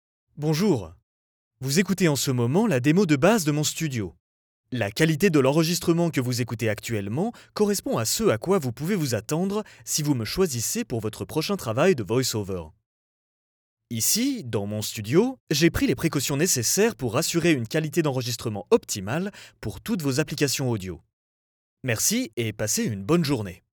中低音域の声で、暖かさと親しみやすさを兼ね備えています。
– ナレーション –
ストレート(フランス語)